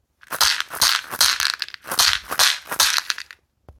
ひょうたん底 バスケットマラカス アフリカ 民族楽器 （n121-17） - アフリカ雑貨店 アフロモード
ブルキナファソで作られたバスケット素材の素朴なマラカスです。
やさしいナチュラルな乾いた音を出します。
説明 この楽器のサンプル音 原産国 ブルキナファソ 材質 水草の茎、ひょうたん、木の実 サイズ 高さ：16cm 最大幅：9cm 重量 52g コメント ※写真の商品をお届けします。